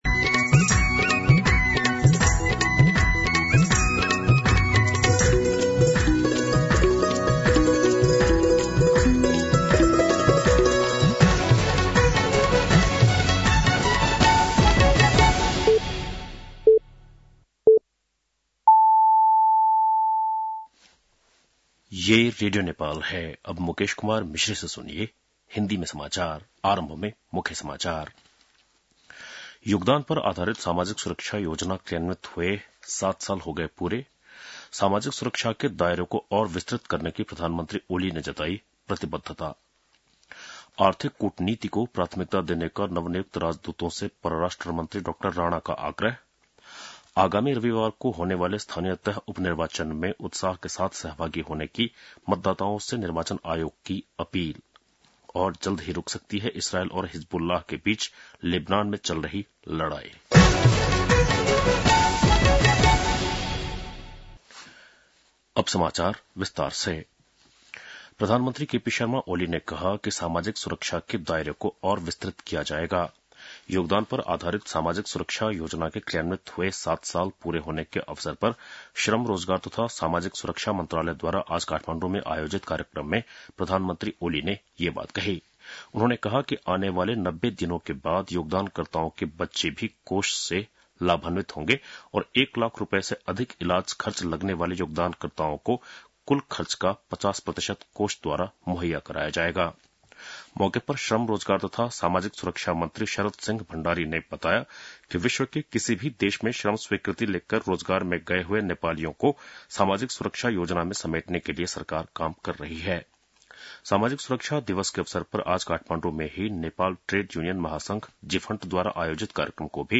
बेलुकी १० बजेको हिन्दी समाचार : १२ मंसिर , २०८१
10-PM-Hindi-News-8-11.mp3